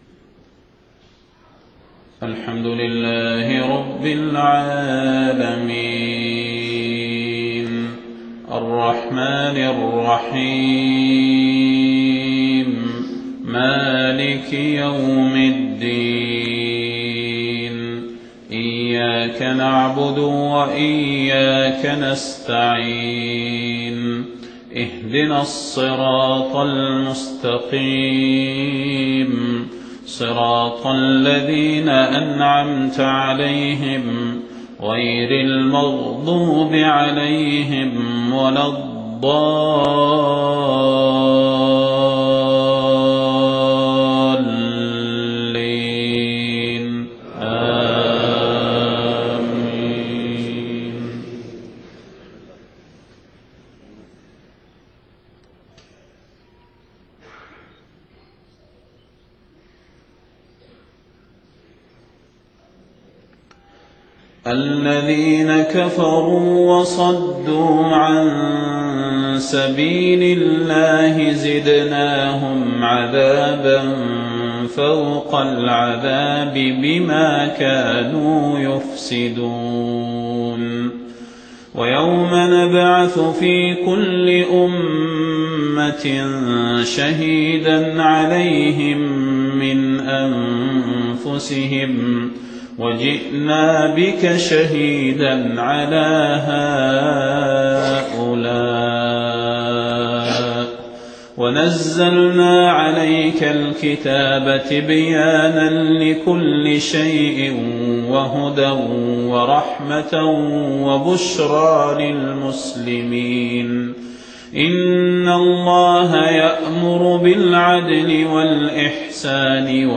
صلاة الفجر 23 محرم 1430هـ من سورة النحل 88-105 > 1430 🕌 > الفروض - تلاوات الحرمين